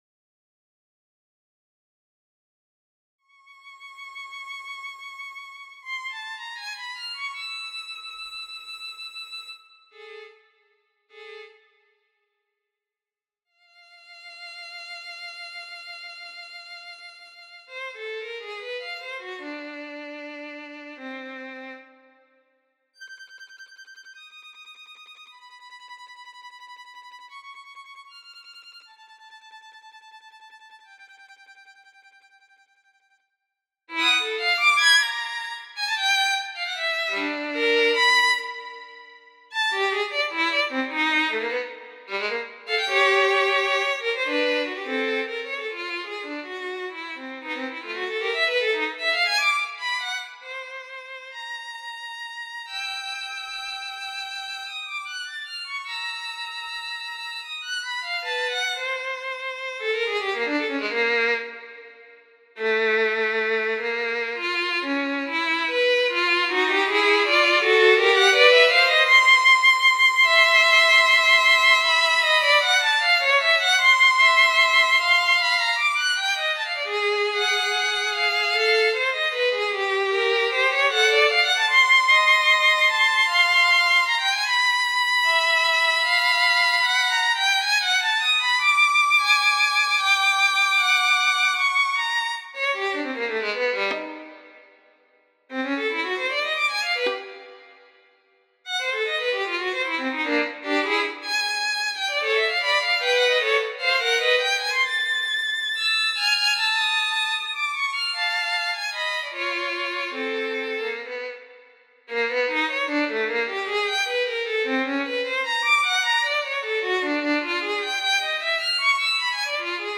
[other instrument]
for Violin solo